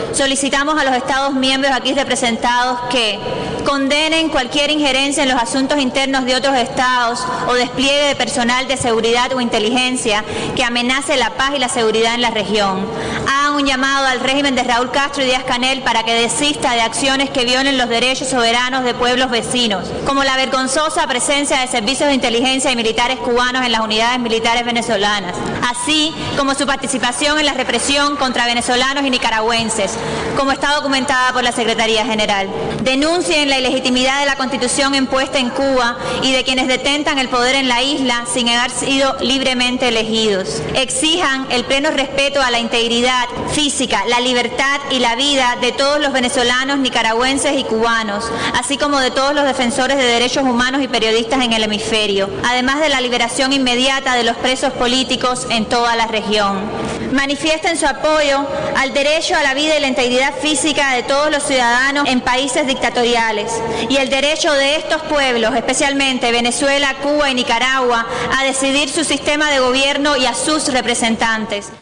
Declaraciones de Rosa María Payá en Medellín